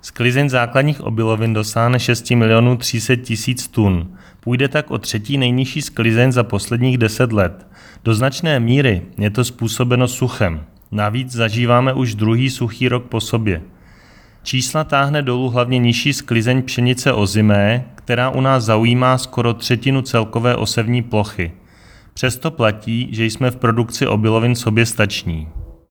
Vyjádření předsedy ČSÚ Marka Rojíčka